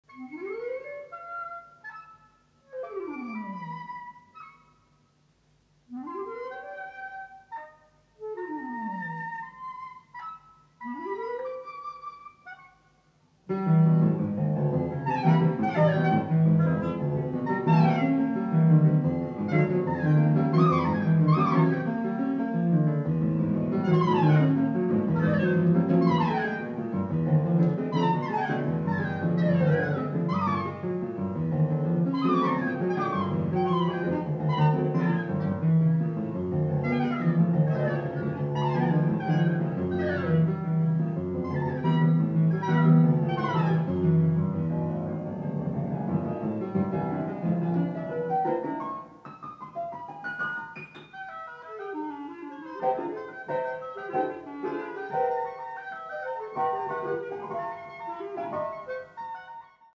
concerto dal vivo
audio 44kz stereo